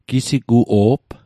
Pronunciation Guide: gi·si·gu·oob Translation: Old man